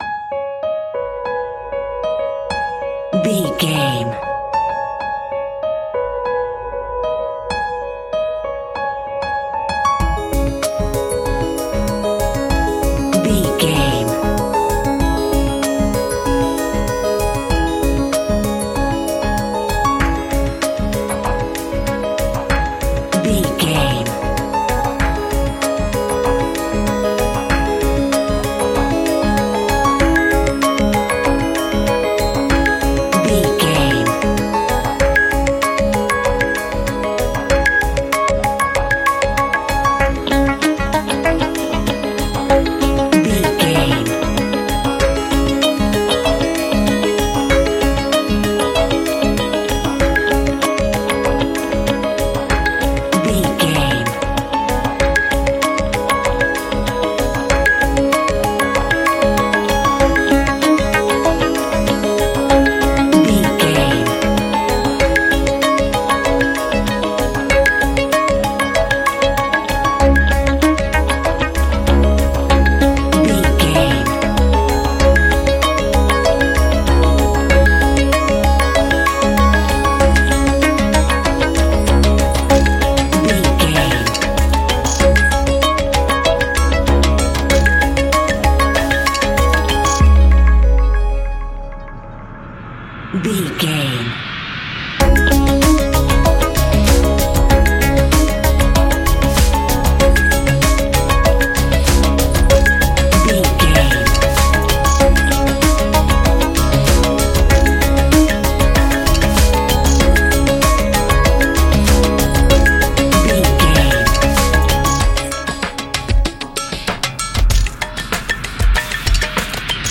Ionian/Major
C♯
electronic
techno
trance
synths
synthwave